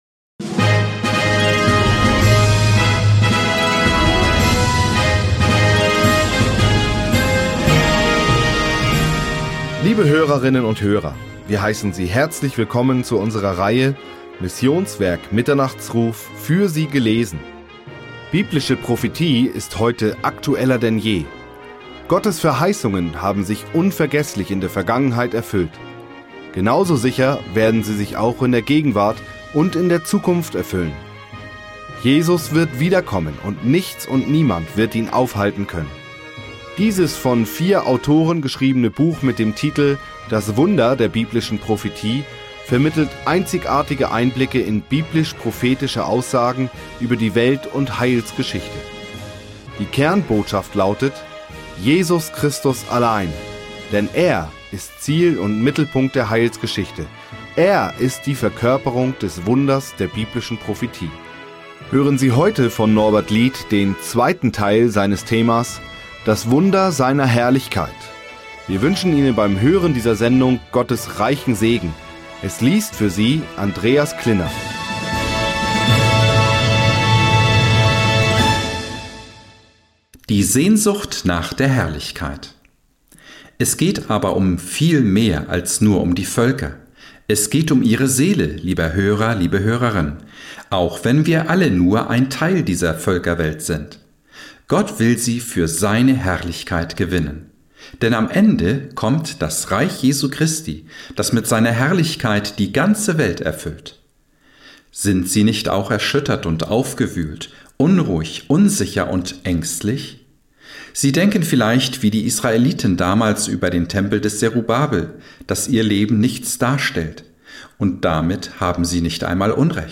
Für Sie gelesen